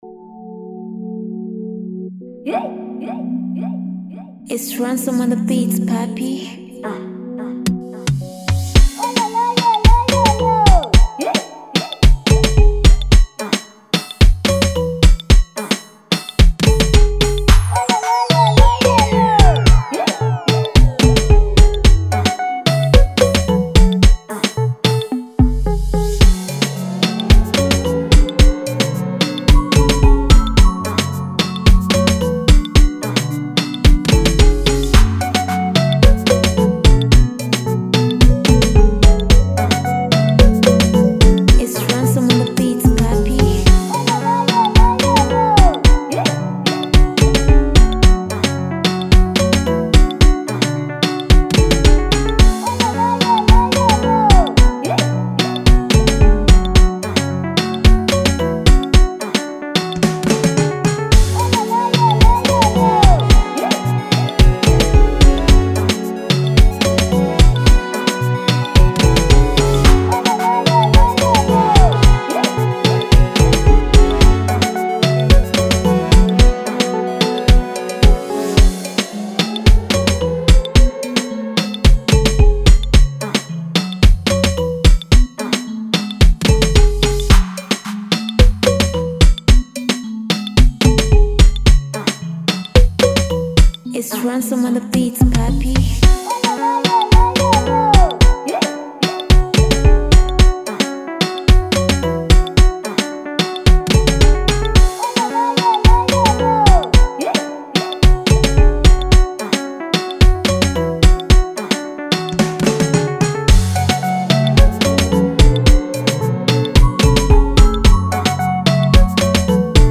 culturally inspired style of music
It’s African.